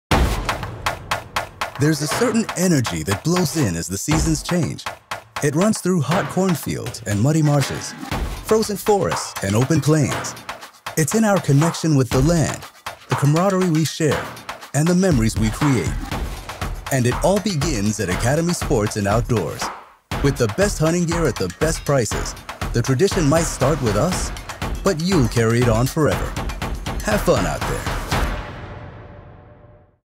Professional American Male Voice Actor | Commercial, E-Learning & Corporate Narration
Commercial Demo
Known for a deep, authoritative voice as well as warm, conversational, and relatable reads, I provide versatile performances tailored to luxury brands, tech explainers, financial narration, medical content, network promos, political campaigns, and cinematic trailers.